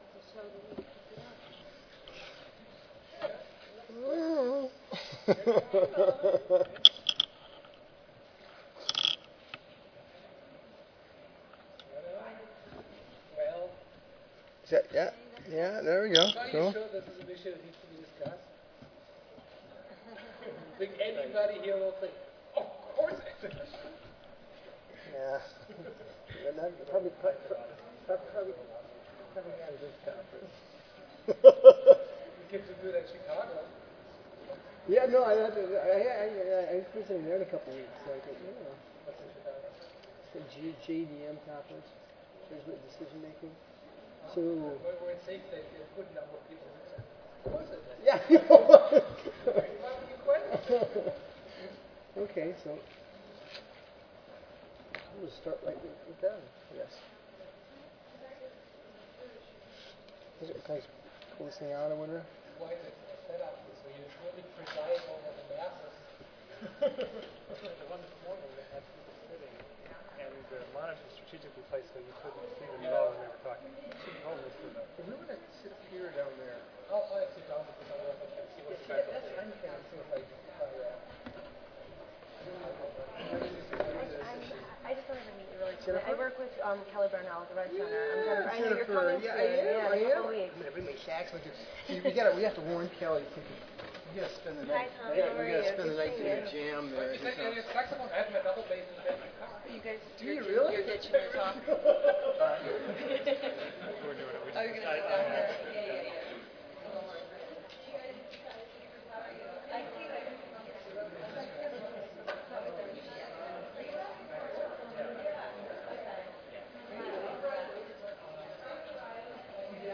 Oral